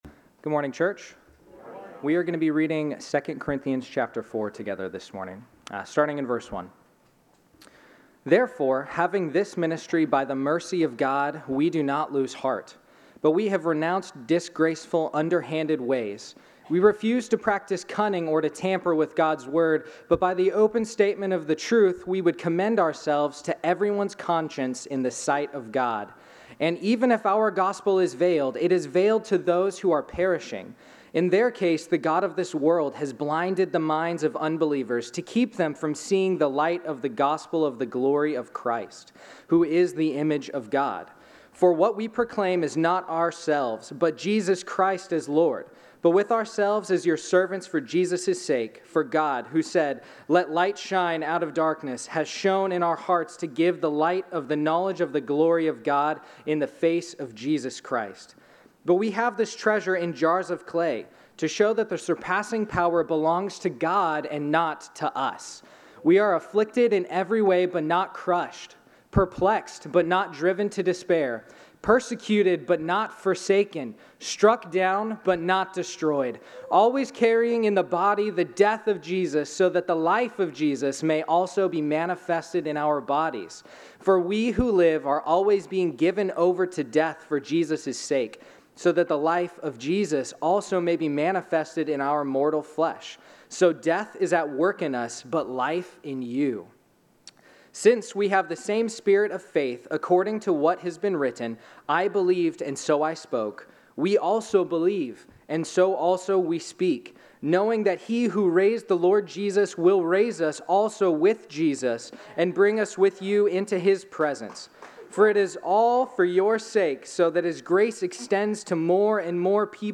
Sermon Notes: Treasure in Jars of Clay